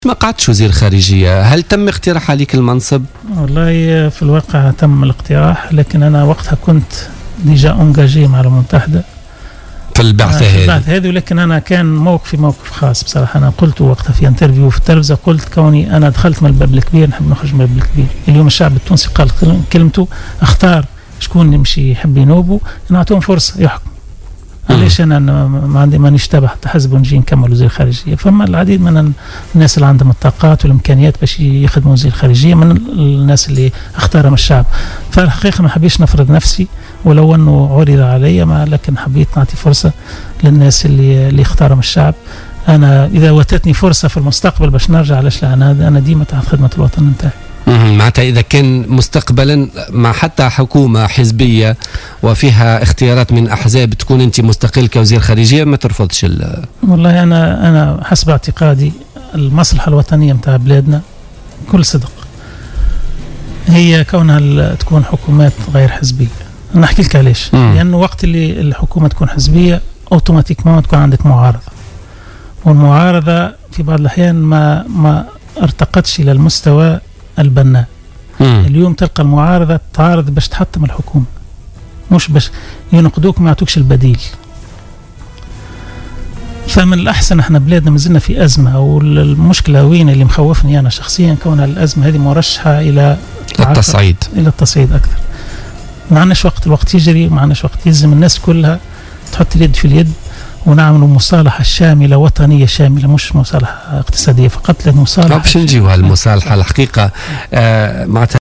Lors de son passage sur le plateau de Jawhara FM dans le cadre de l’émission Politica du mardi 18 août 2015, l'ancien ministre des Affaires étrangères, Mongi Hamdi, a révélé, que le poste de ministre des affaires étrangères lui a de nouveau été proposé.